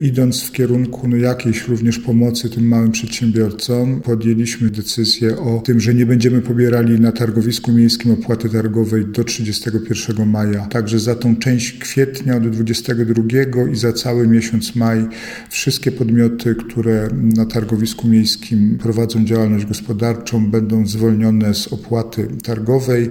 – Podjęliśmy decyzję, aby do końca maja zwolnić naszych kupców z tych opłat – powiedział prezydent Jacek Milewski: